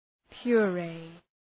Προφορά
{pjʋ’reı}
puree.mp3